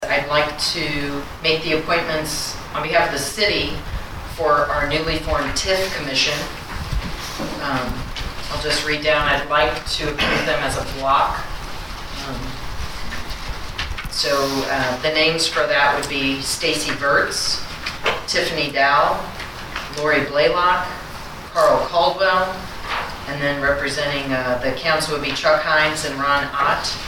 During her report at the Marshall City Council meeting on Monday, July 1, Mayor Julie Schwetz asked the council to approve six appointments to the TIF Commission.